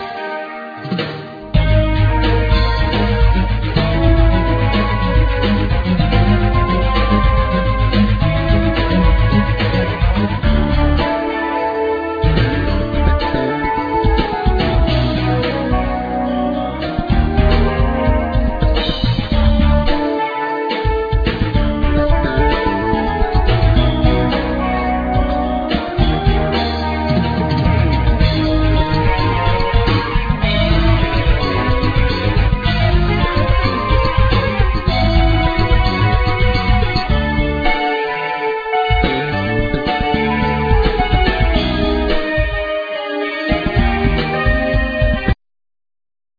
Guitar,Keyboard,Sequencer
Violin,Viola
Drums,Percussion
Clarinett
Double Bass
Cello
Flute